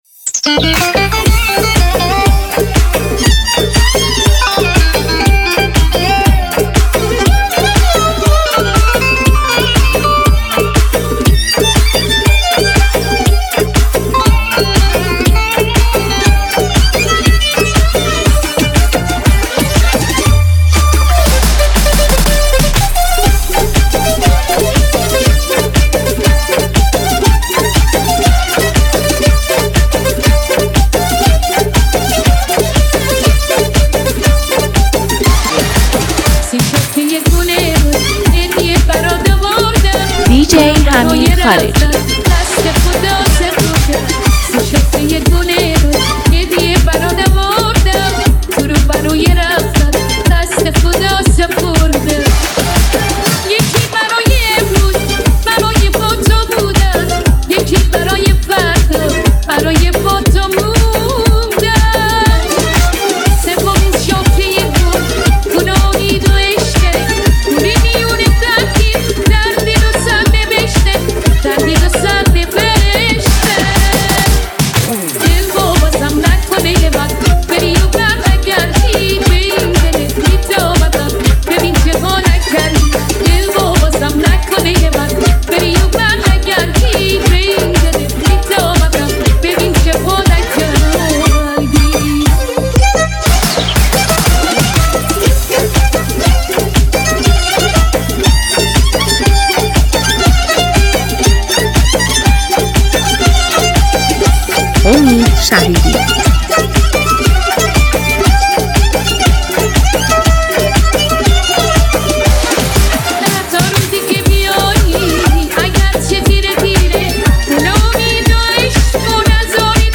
این میکس شاد رو از دست ندید!
یه کار فوق العاده شاد و پرانرژی که مطمئناً خوشتون میاد.